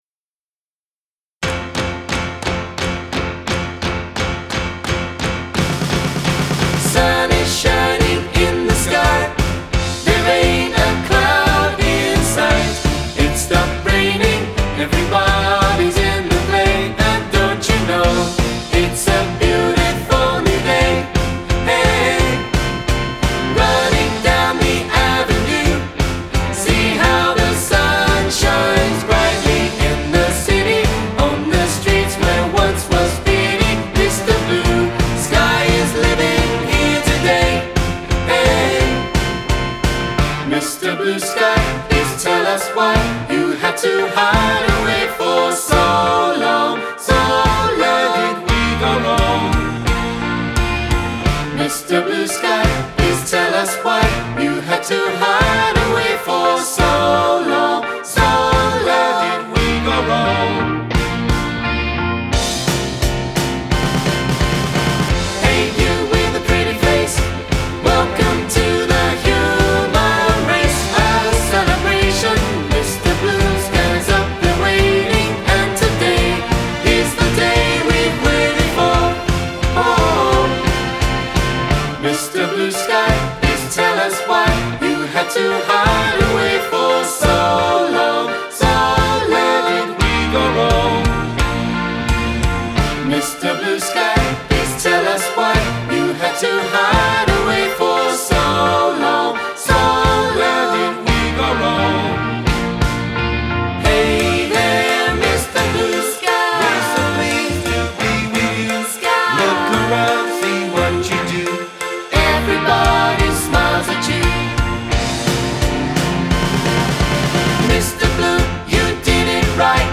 SATB
Band
Arranged for SATB mixed voices with amateur singers in mind